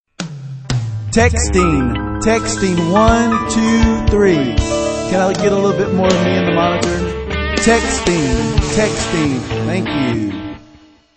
Kategorien: Sms Töne